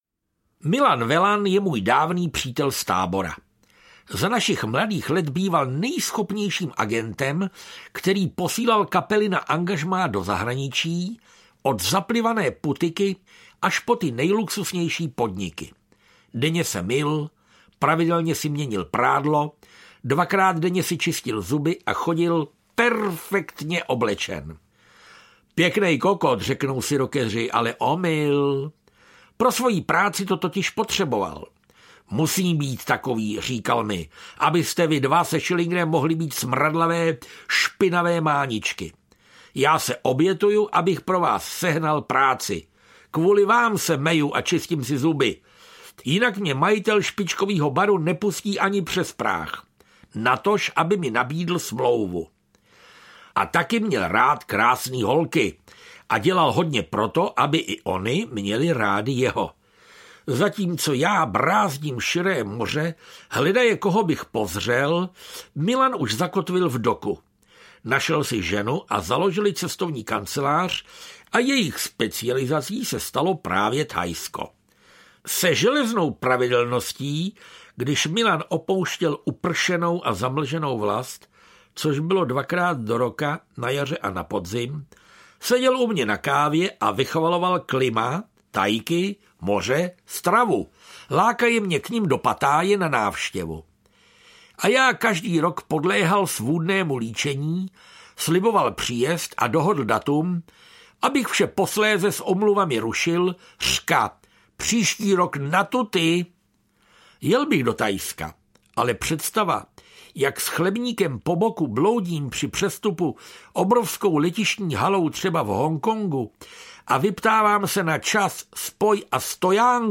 Jako Čuk a Gek audiokniha
Audiokniha Jako Čuk a Gek - obsahuje osobitý cestopis, jehož autory i vypravěči jsou Petr Novotný, František Ringo Čech. Úsměvné putování světem křížem krážem
Ukázka z knihy
• InterpretFrantišek Ringo Čech, Petr Novotný